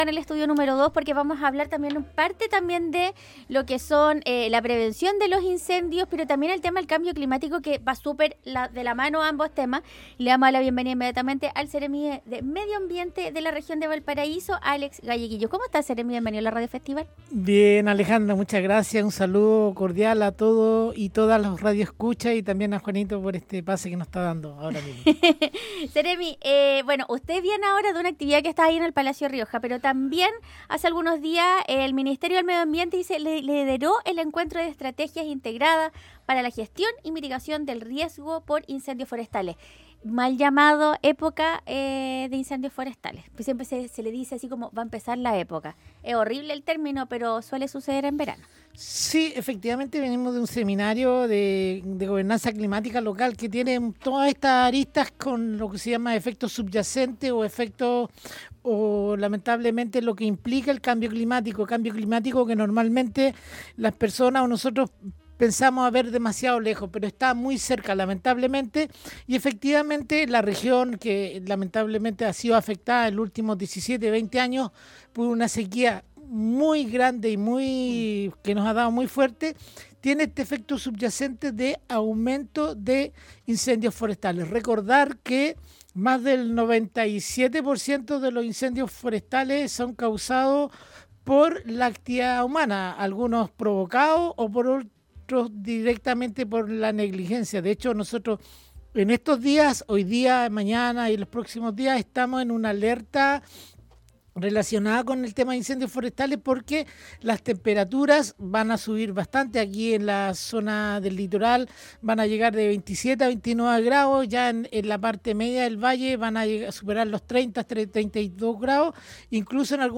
El Seremi de Medio Ambiente de la Región de Valparaíso, Alex Galleguillos contó detalles de los planes que desarrolla el Ministerio para evitar incendios forestales y enfrentar el cambio climático.